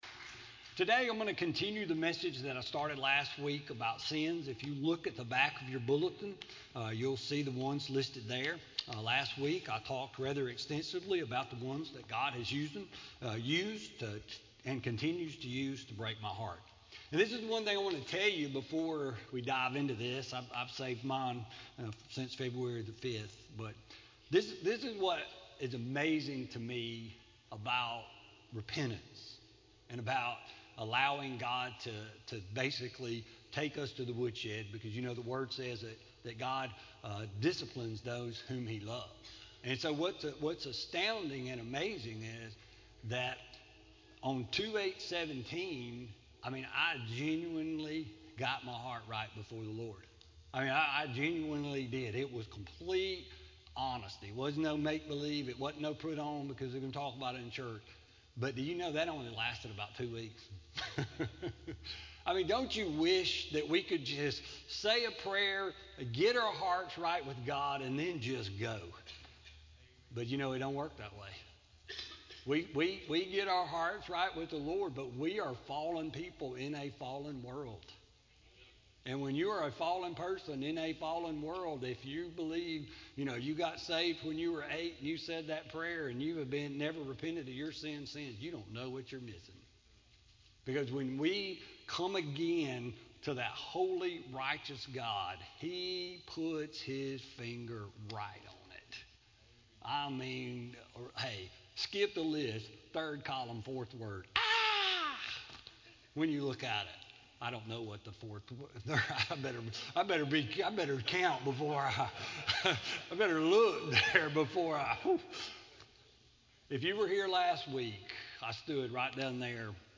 Sermon-2-26-17-CD.mp3